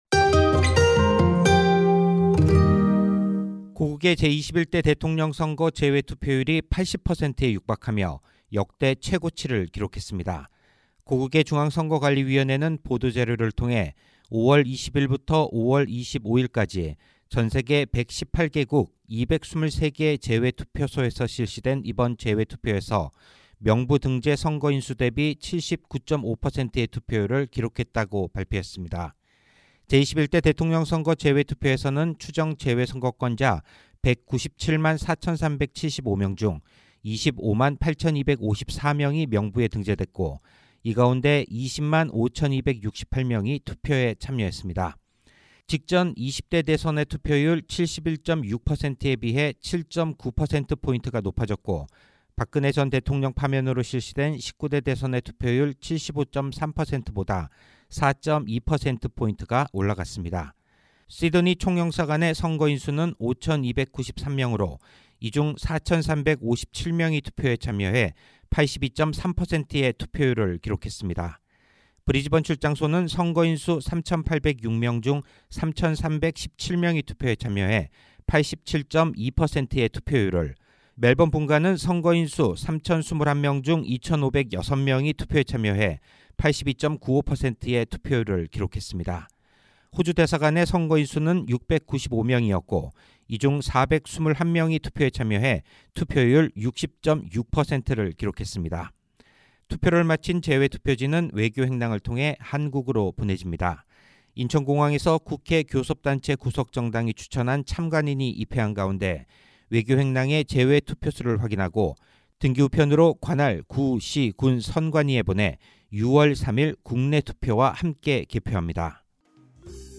SBS Korean 01:44 Korean 상단의 오디오를 재생하시면 뉴스를 들으실 수 있습니다.